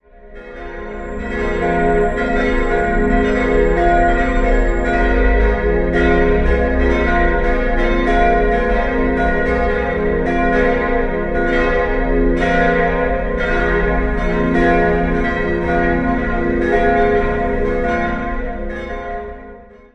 4-stimmiges ausgefülltes F-Moll-Geläute: f'-as'-b'-c'' Die Glocken 1 und 4 (gegossen 1962) sowie 3 (gegossen 2005) wurden von Petit&Edelbrock in Gescher gegossen, Glocke 2 stammt aus der Gießerei Otto in Bremen-Hemelingen und wurde 1919 hergestellt.